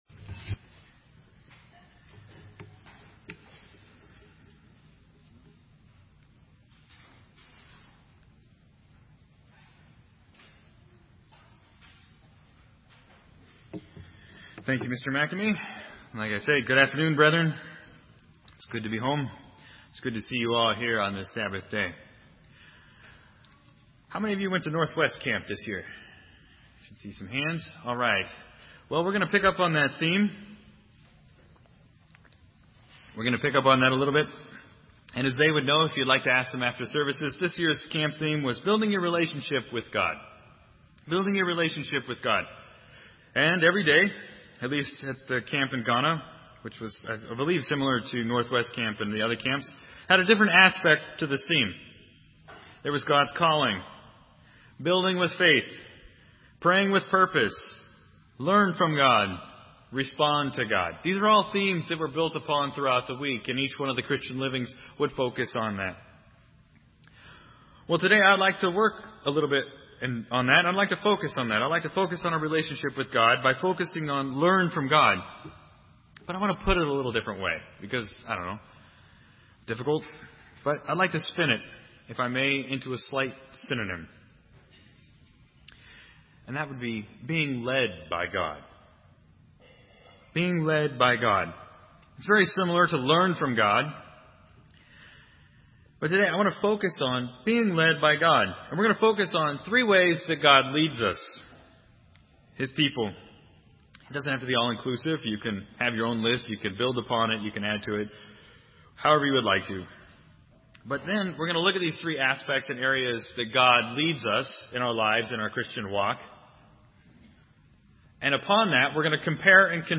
Being led by and following God is critical in our Christian walk. In today's sermon we will focus on three ways in which God leads us.